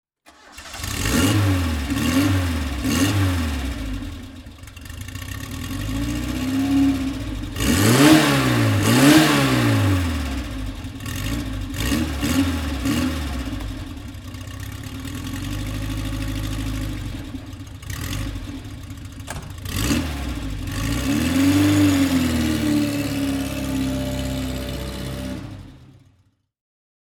Matra-Simca Bagheera (1974) - Starten und Leerlauf
Matra-Simca_Bagheera_1974.mp3